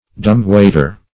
Dumb-waiter \Dumb"-wait`er\, n.
dumb-waiter.mp3